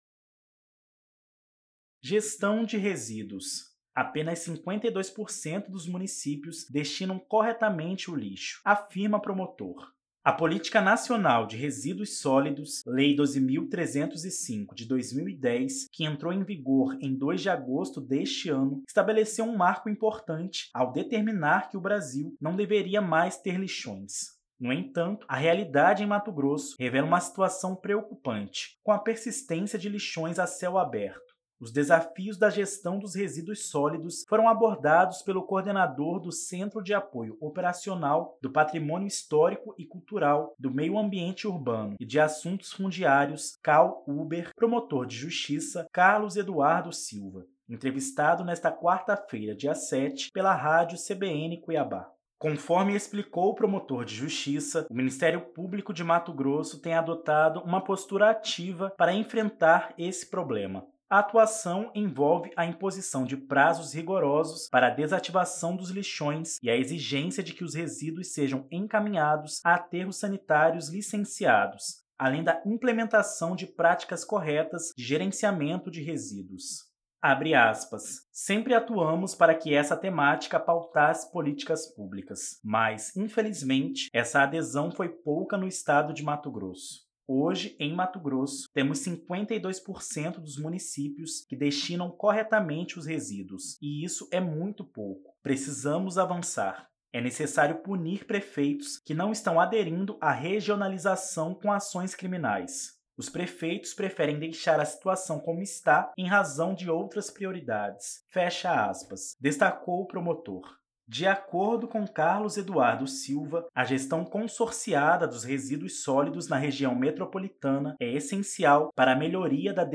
Os desafios da gestão dos resíduos sólidos foram abordados pelo coordenador do Centro de Apoio Operacional do Patrimônio Histórico e Cultural, do Meio Ambiente Urbano e de Assuntos Fundiários (CAO Urbe), promotor de Justiça Carlos Eduardo Silva, entrevistado nesta quarta-feira (7) pela Rádio CBN Cuiabá.